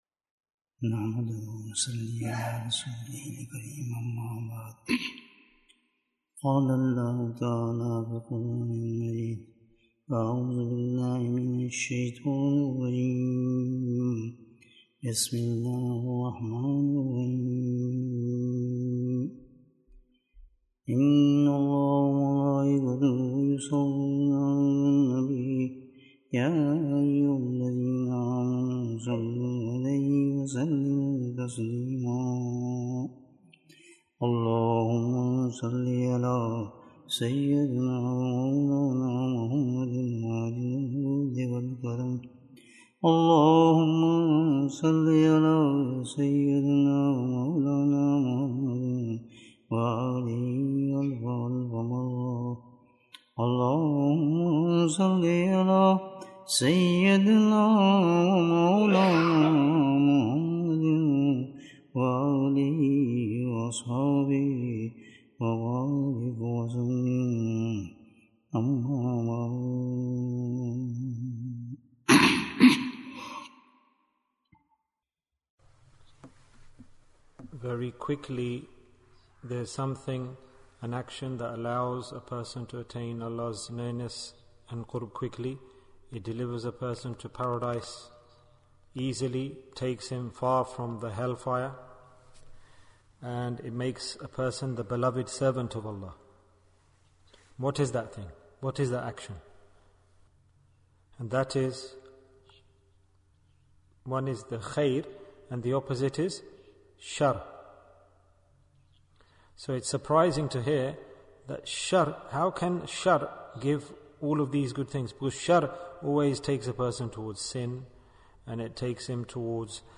Tawbah in Rajab Bayan, 72 minutes26th January, 2023